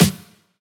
taiko-soft-hitnormal.ogg